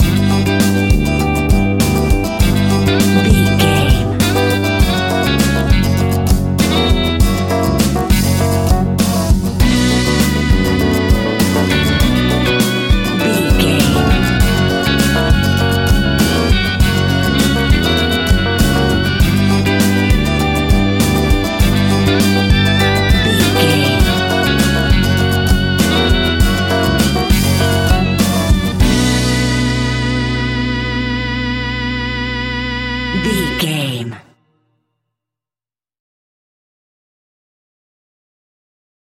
Aeolian/Minor
flamenco
salsa
romantic
maracas
percussion spanish guitar
latin guitar